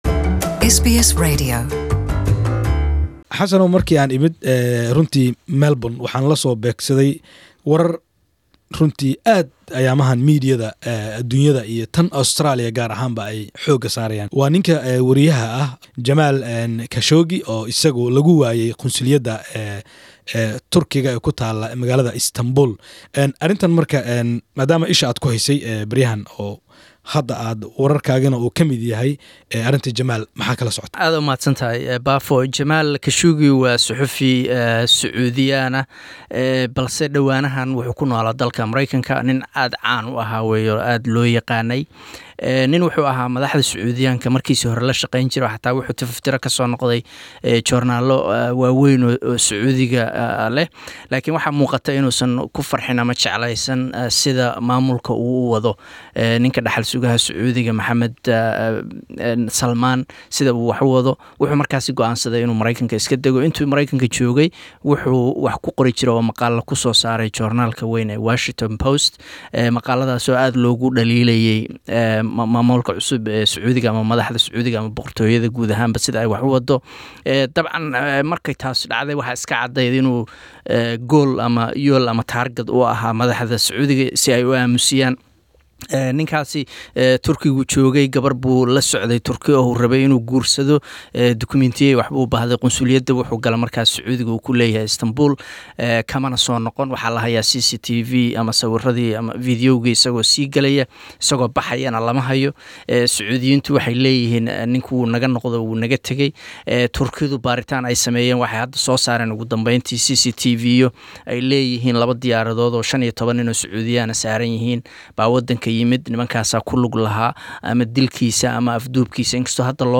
Warar goosgoos ah.